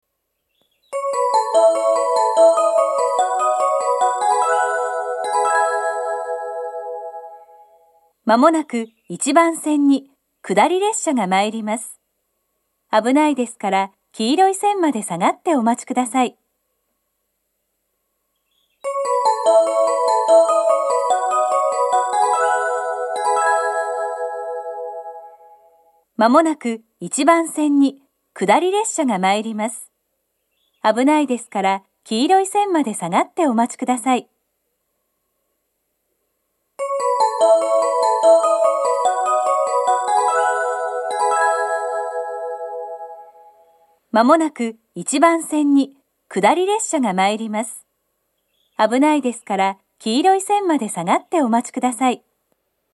この駅の放送は東北でよく聞ける放送ではなく、カンノの放送です。接近放送は１・２番線は２回、３番線は３回流れます。
１番線接近放送